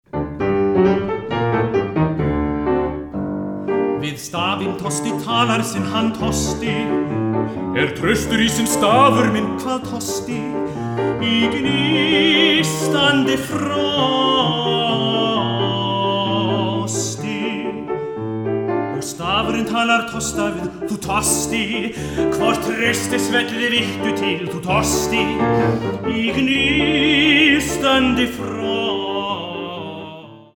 d1 – e2
Hann Tosti (sóló)